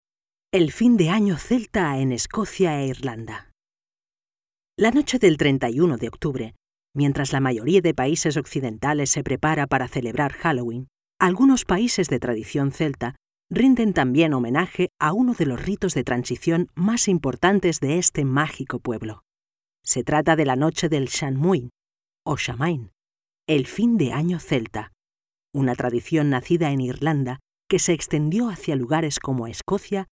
I have a versatile voice that can make different voices, and very different intentions in the same voice, if necessary.
Sprechprobe: Industrie (Muttersprache):
My recording equipment is: Neumann condenser microphone TLM 102, Previous Fucusrite Scarlet 2/2 and Software logic pro X and Audition.